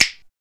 PRC SNAPS 0D.wav